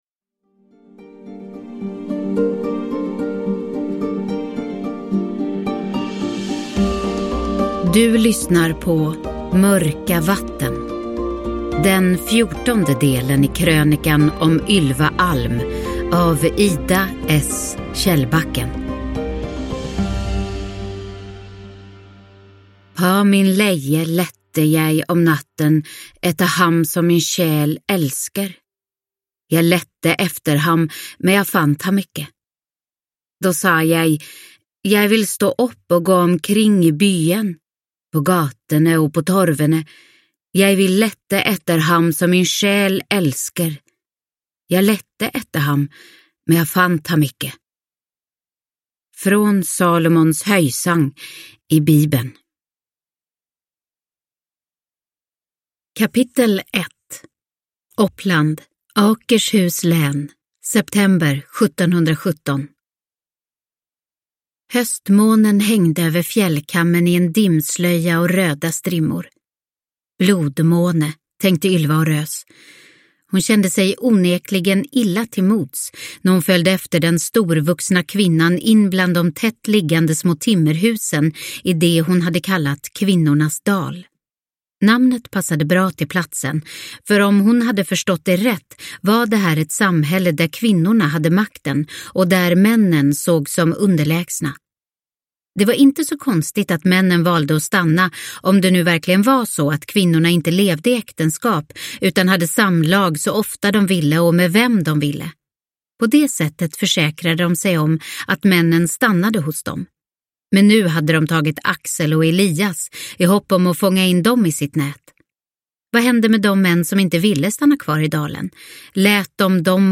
Mörka vatten – Ljudbok – Laddas ner